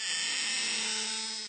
basket_open.ogg